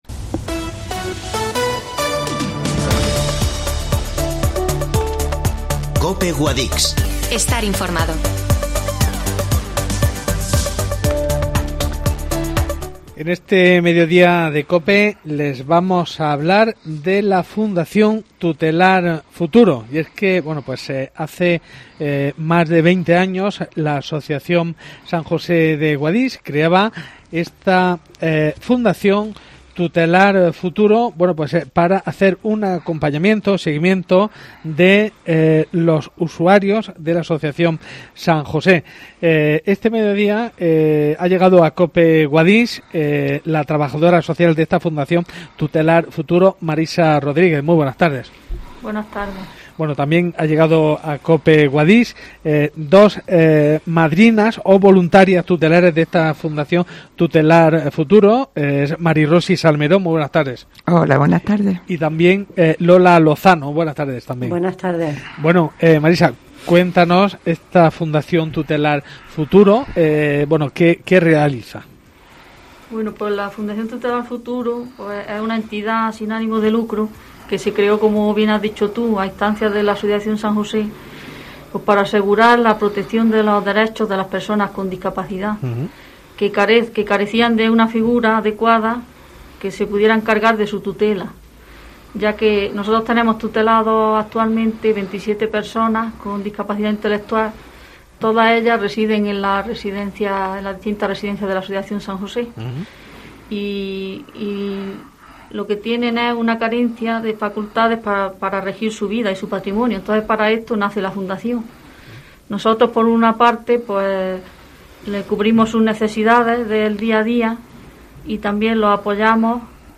Entrevista Fundación Tutelar Futuro